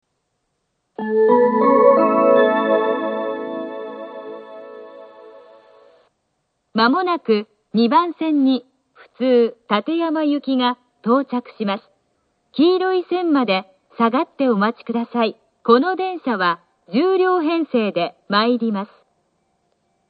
２番線接近放送 普通館山行（１０両）の放送です。